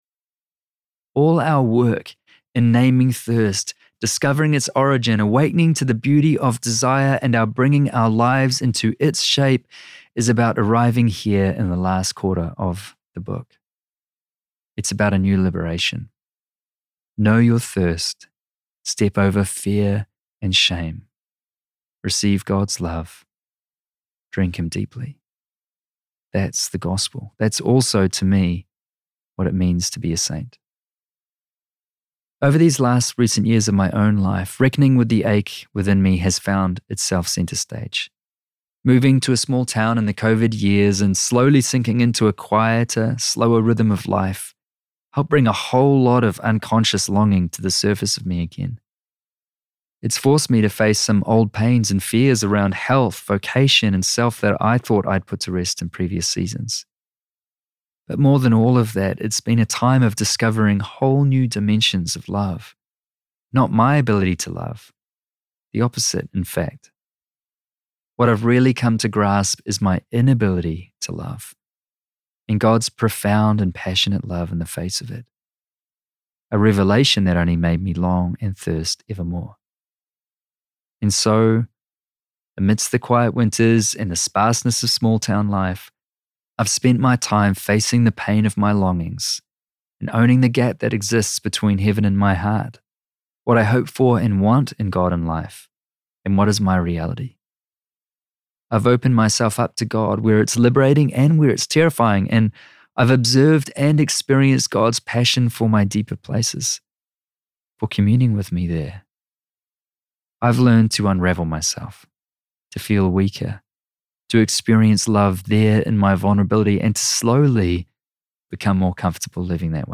Audio Book Sample